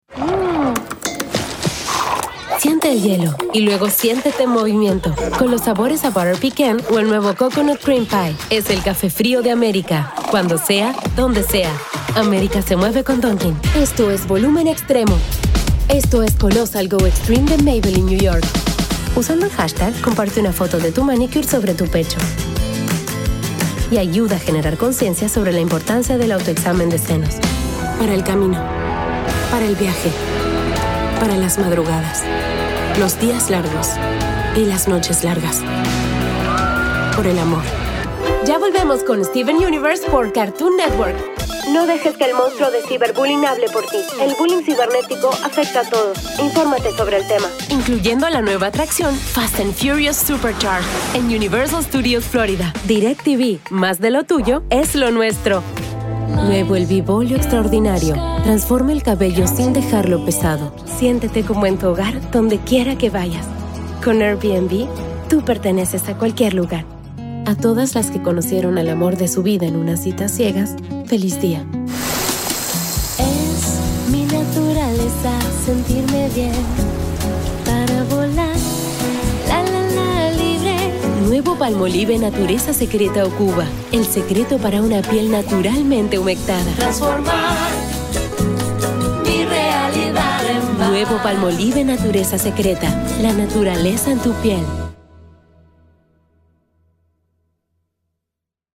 Female Voice Over, Dan Wachs Talent Agency.
Motivating, Conversational, Believable, Warm
Commercial